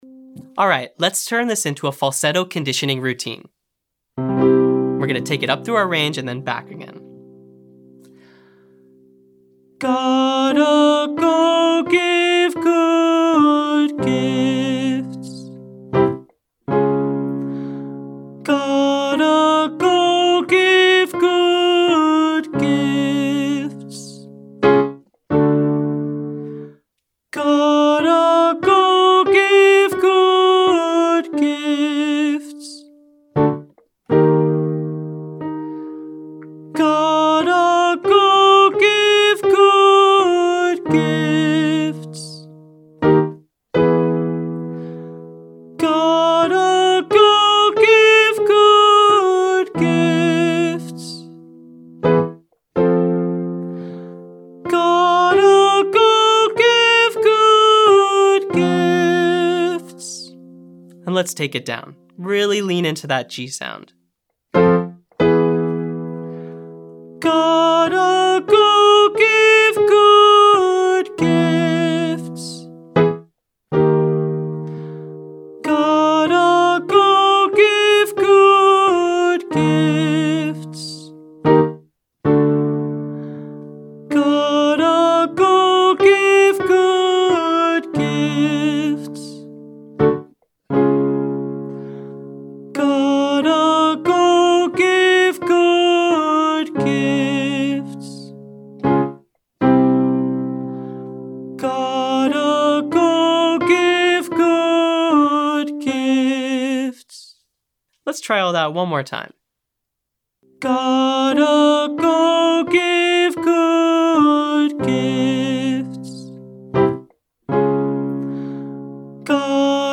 Cord Compression - Online Singing Lesson
• Glottal K Exercise (Cuckoo Clock 53,53) to condition the vocal folds with glottal pressure.
• Sing Freely (1-54321) with an NG sound to feel proper resonance in head voice.